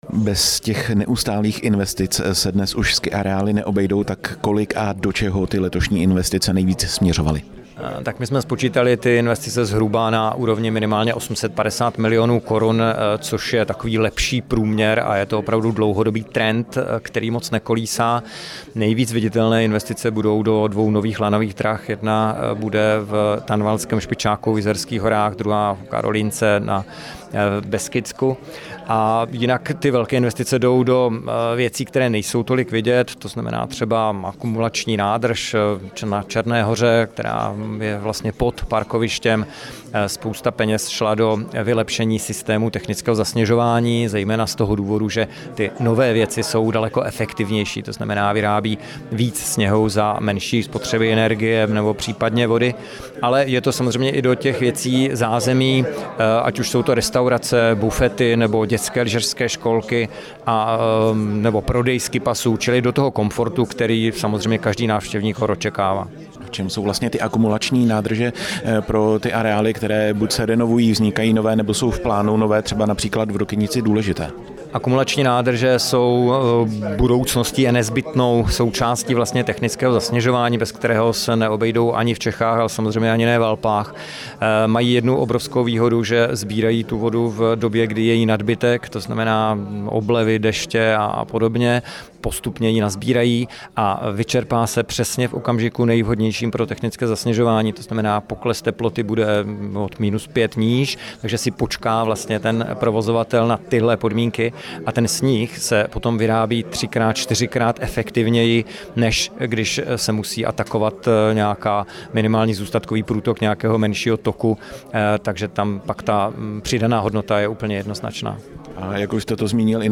Rozhovory